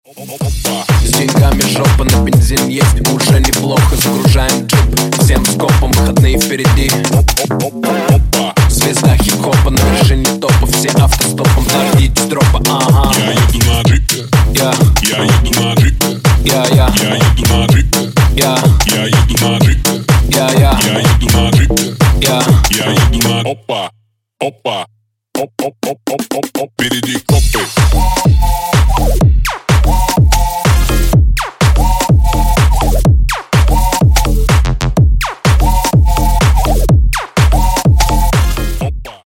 Рингтоны Ремиксы
Танцевальные Рингтоны